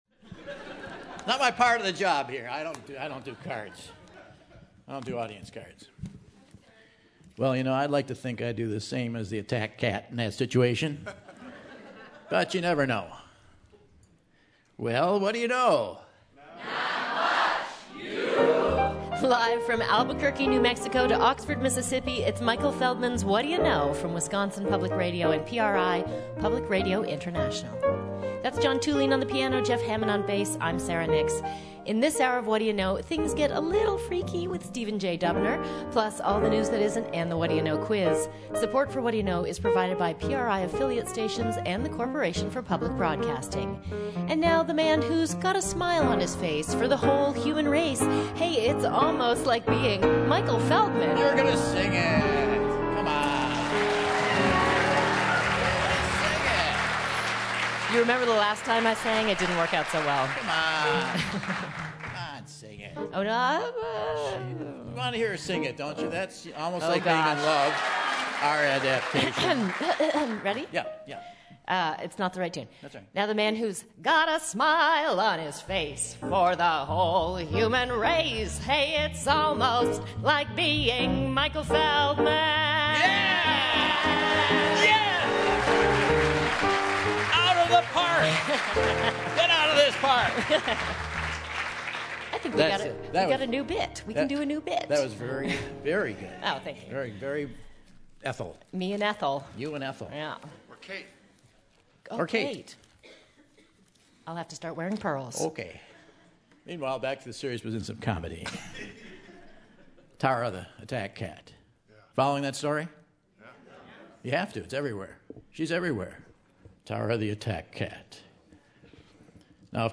May 17, 2014 - Madison, WI - Monona Terrace | Whad'ya Know?